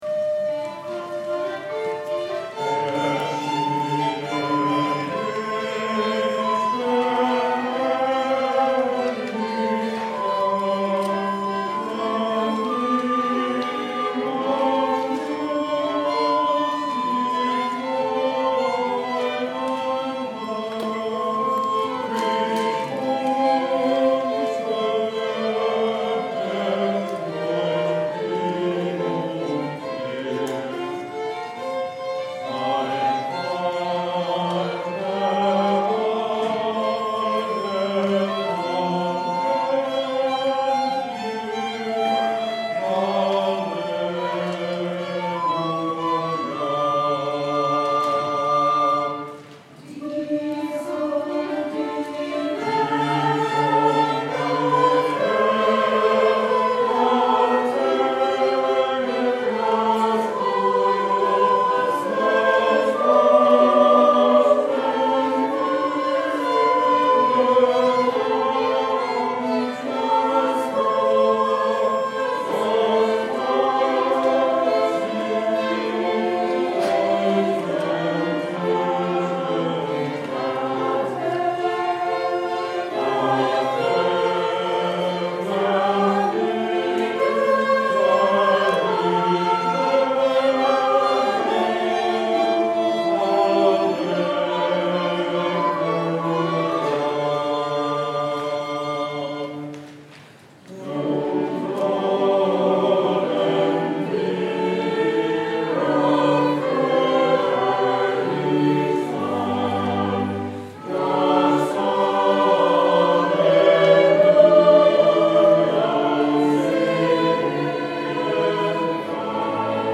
COS’s choral year finished up this Sunday, and I must say we went out in style.
First up was a prelude, “
Ershienen Ist der Herrlich Tag” (The Glorious Day Has Appeared), a beautiful Easter chorale harmonized by J.S. Bach.